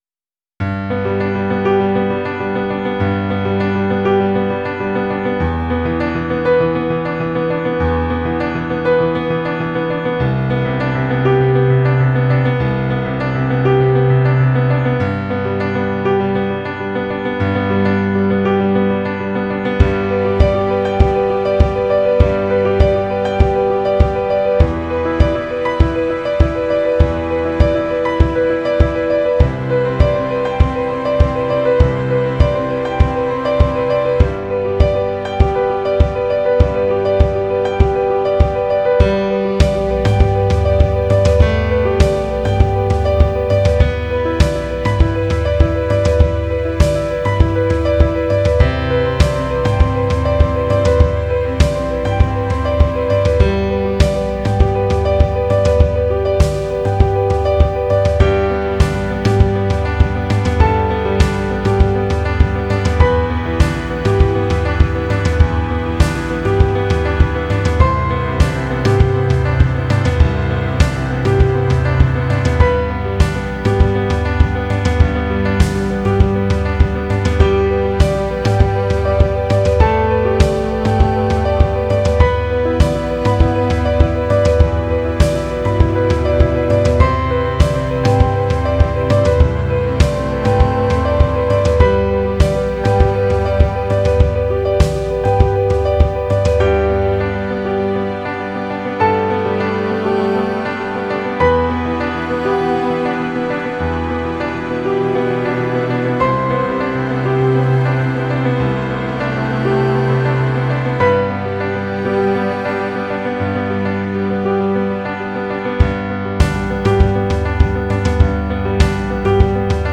это трек в жанре инди-поп, наполненный ностальгией и теплом.